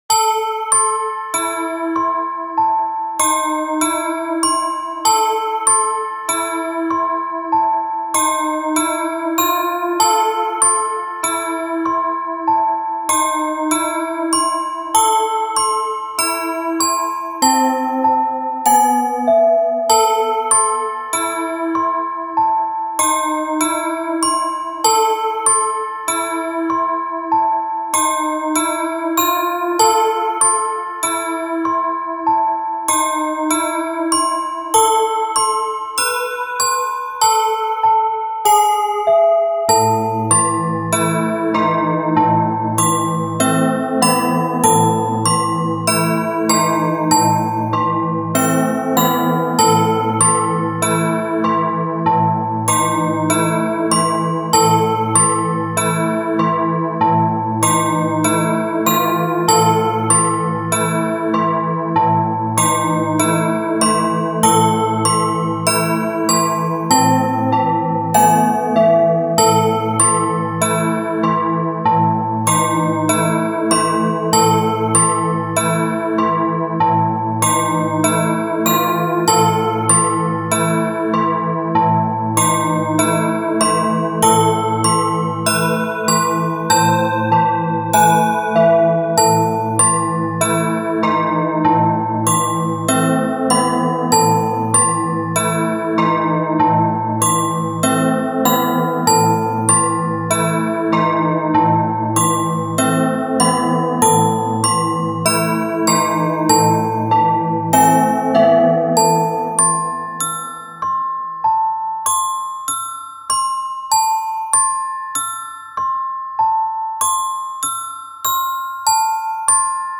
ベルのみの不安になる暗くミステリアスな曲です。
グロッケン,シンセベル,チェレスタ